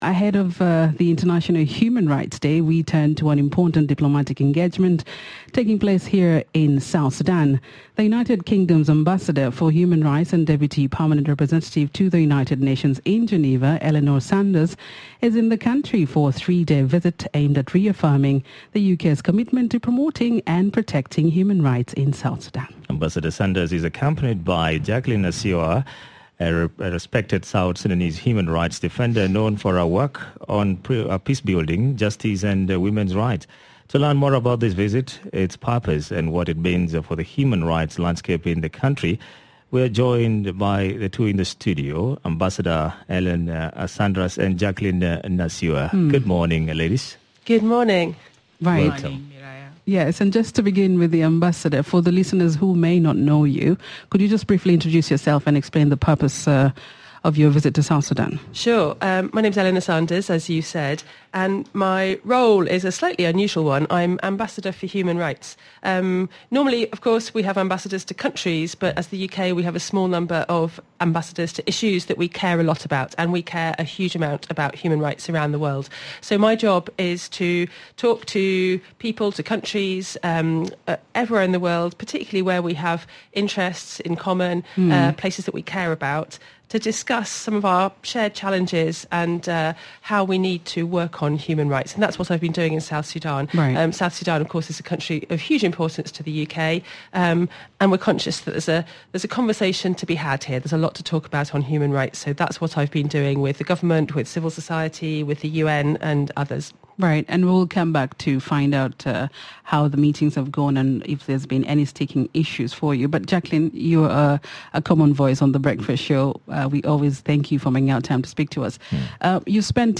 UK Commitment to Human Rights: Ambassador Sanders Visit to South Sudan Episode 3168, Dec 05, 07:40 AM Headliner Embed Embed code See more options Share Facebook X Subscribe Guests: - Eleanor Snaders, UK Ambassador for Human Rights and Deputy Permanent Representative to the United Nation.